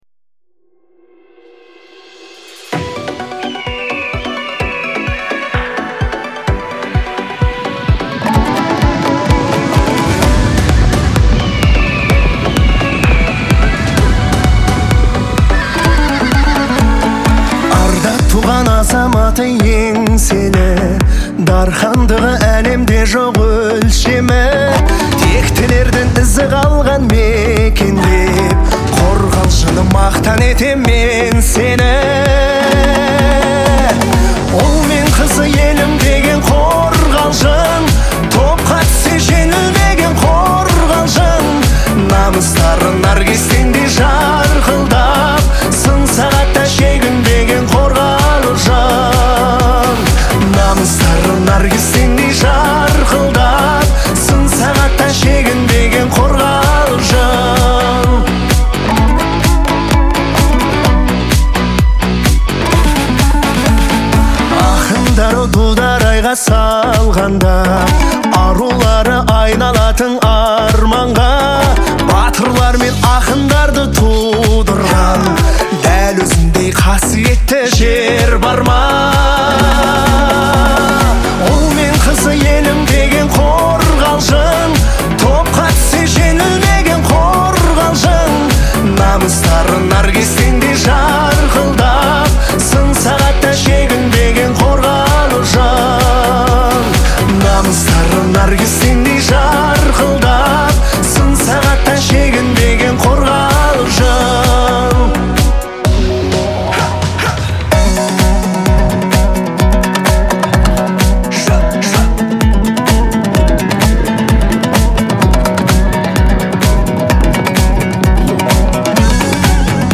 казахского поп-фолка